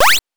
jump_15.wav